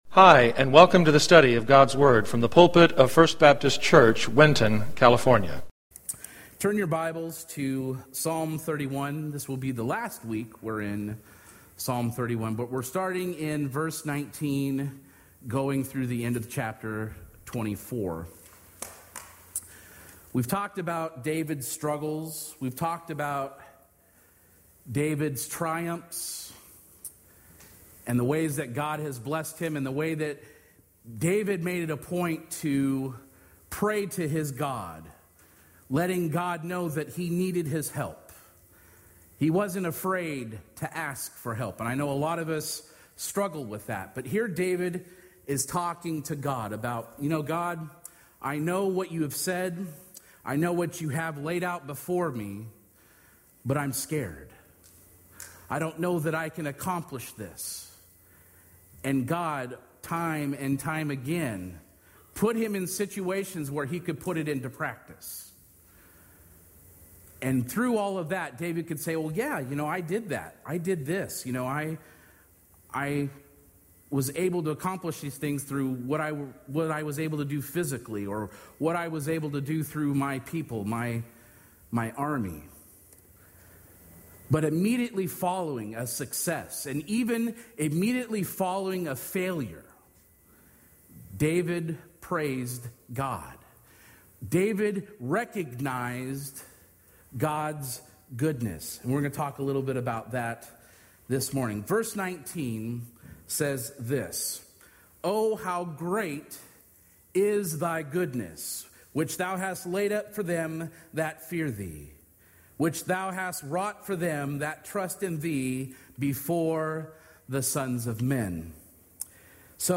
Sermons | Winton First Baptist Church